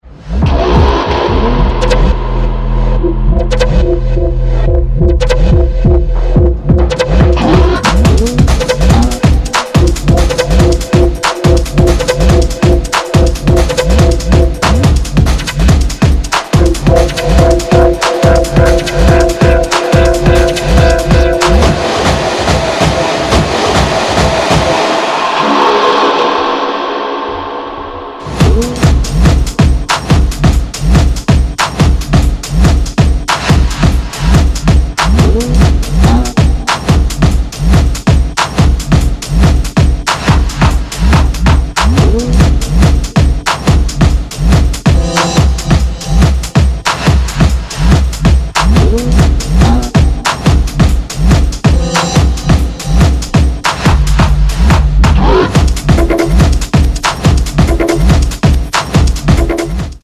dupstep for the headz